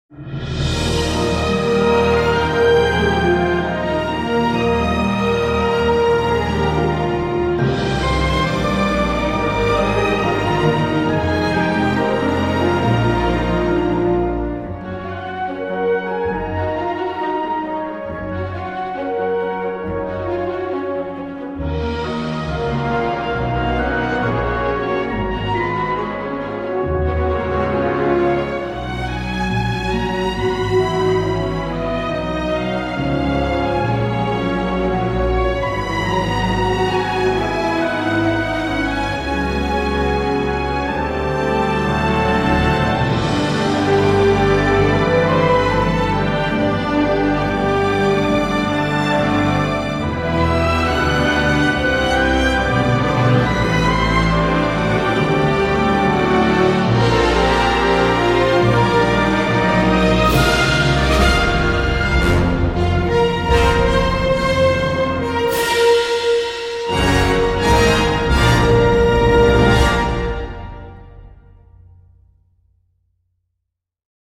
énergie orchestrale habituelle